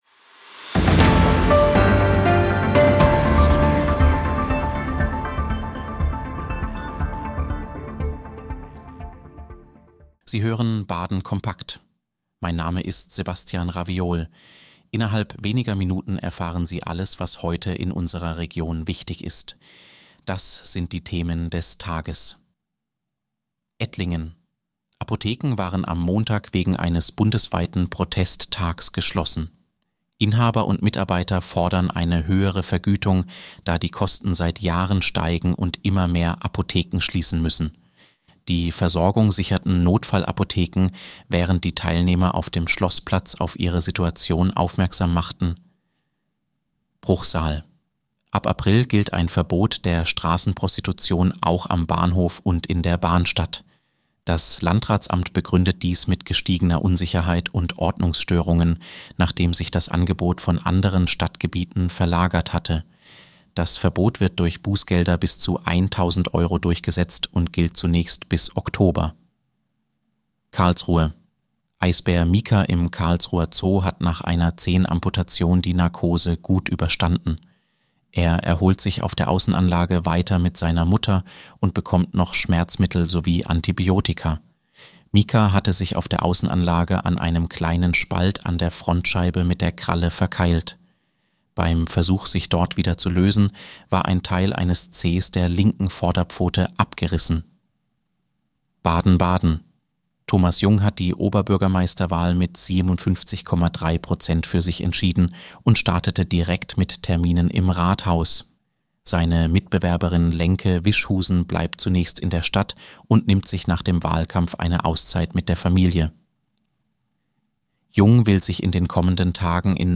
Nachrichtenüberblick: Apotheken wegen Streik geschlossen